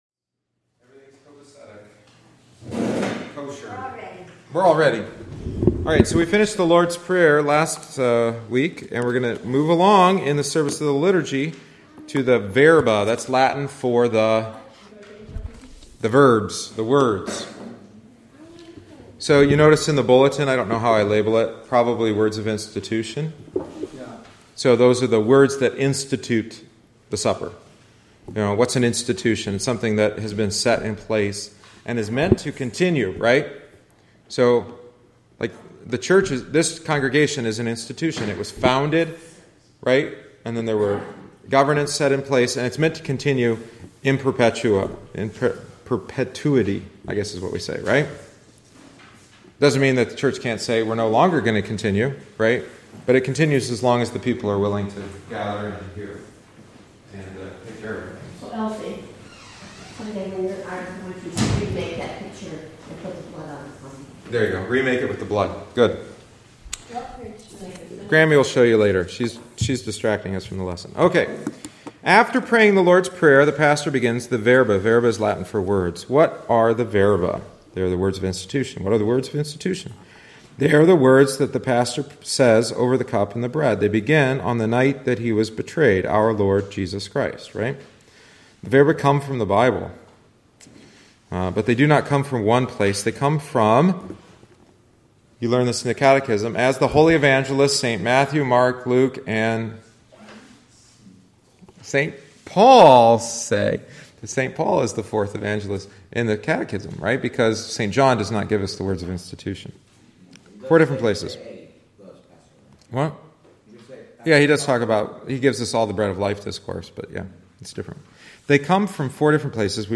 We gather regularly for instruction in the Word of God, not simply to learn knowledge about God, but that our faith in Jesus Christ might be strengthened, and that we might live by that faith in our lives. We call this kind of teaching “catechesis.”
Join us each week after Divine Service (~10:30am) for coffee, treats, and a study of a part of the Liturgy.